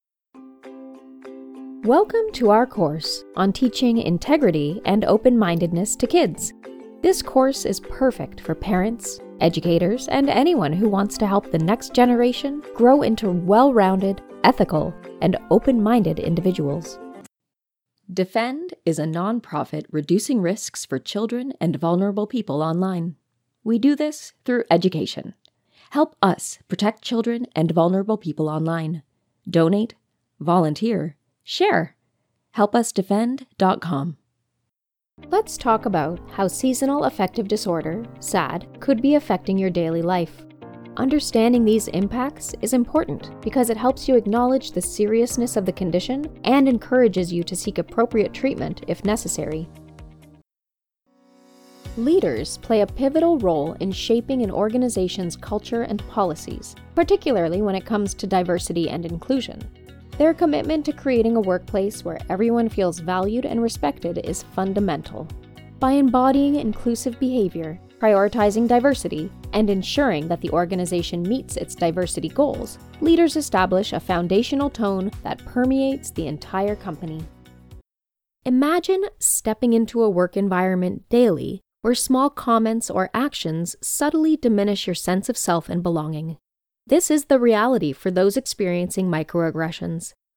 Corporate Narration
English - USA and Canada